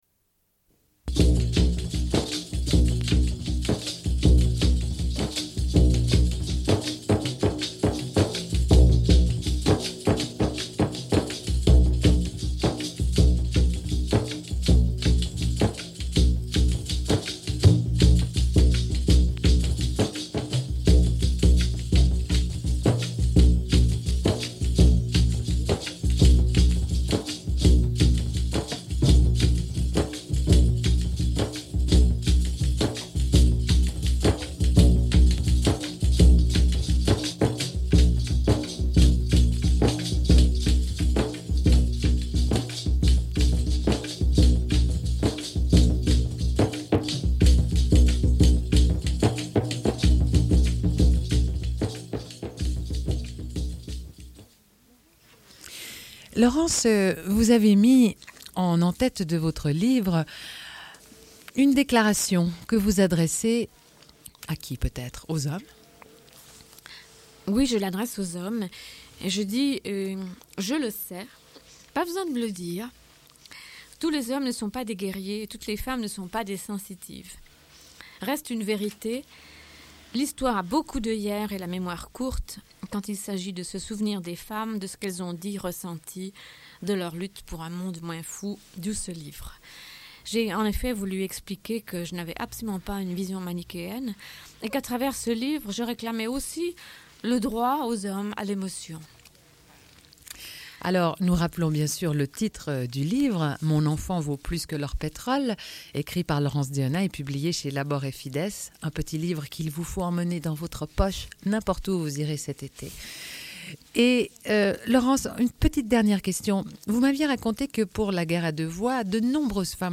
Une cassette audio, face A00:31:20
Radio Enregistrement sonore